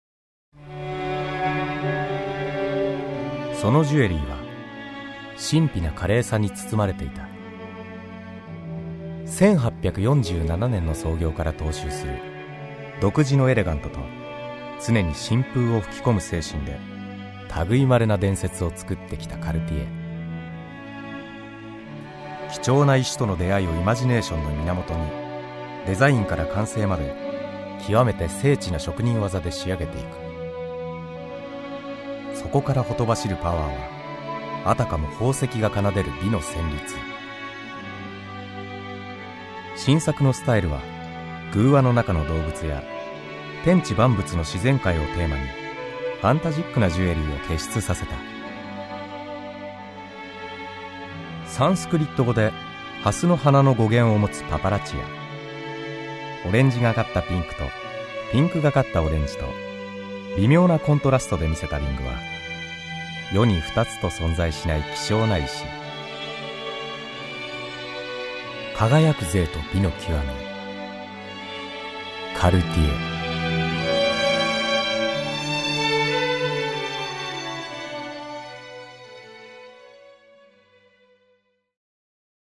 声のタイプ：響きのある中低音
One shot Voice（サンプルボイスの視聴）
どこかにノスタルジーを感じさせるパウダーボイス。